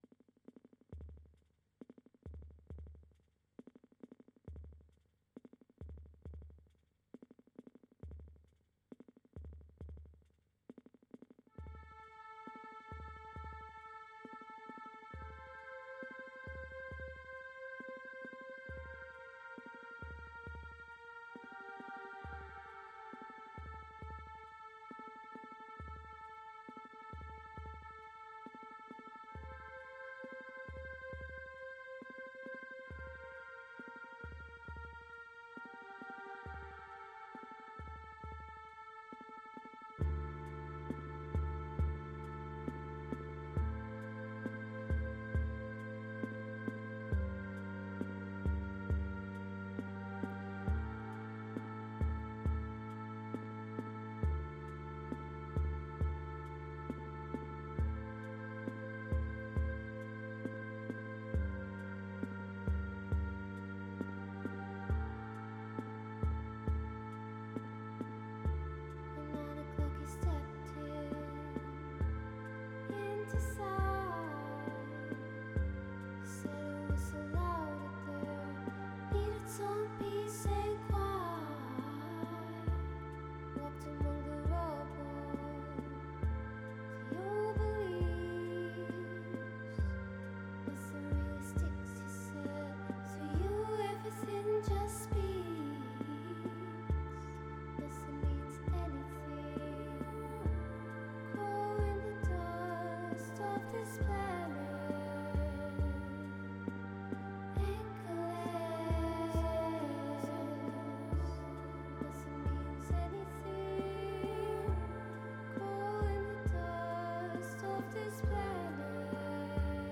1pm SHE ARRIVED AMID HORSES is a radio special hosted...
expect the sound of crickets, manifestos, current song obsessions, overheard poems, dollar bin tapes, and the like, with a lingering emphasis on underground musicians that defy genre and expectation.